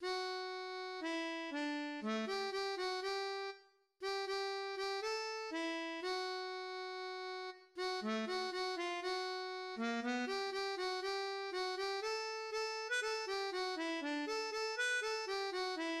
"John Jacob Jingleheimer Schmidt" is a traditional children's song that originates from the United States and Canada. The song consists of one verse repeated, each time increasing or decreasing in volume or tempo.